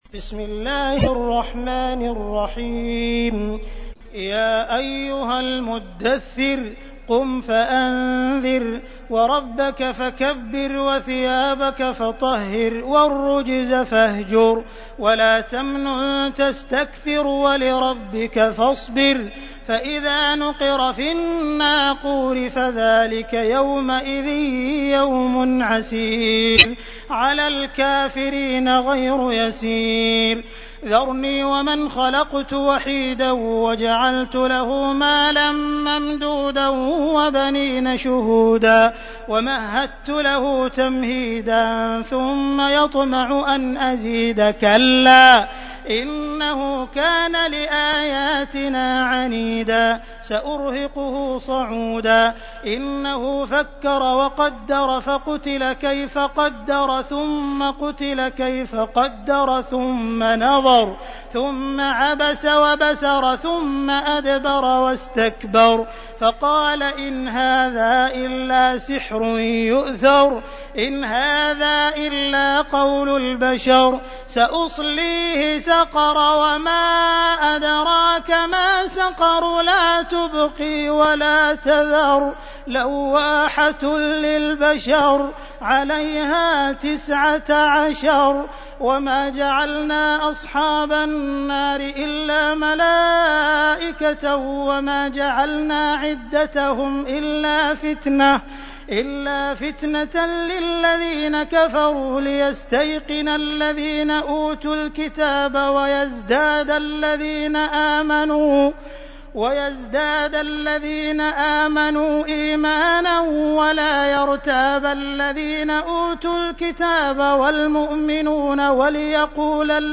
تحميل : 74. سورة المدثر / القارئ عبد الرحمن السديس / القرآن الكريم / موقع يا حسين